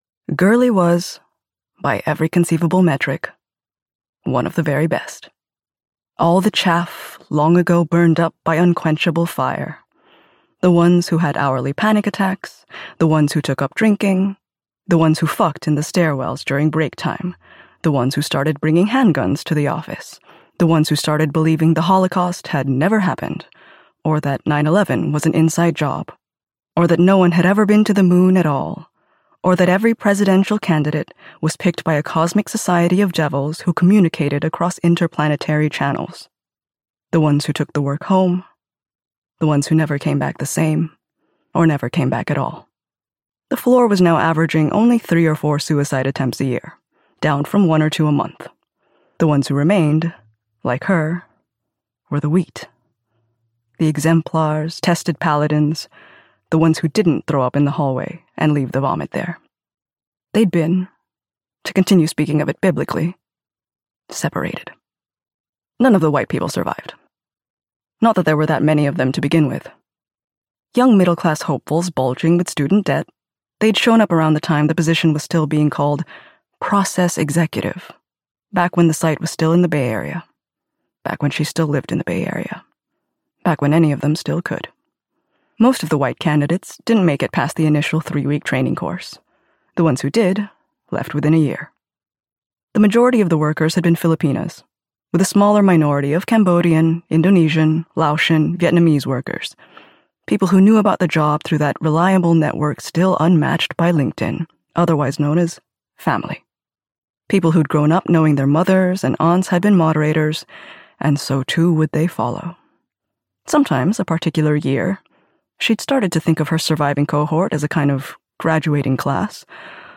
Audiobook sample
Moderation-Chapter-1-Sample.mp3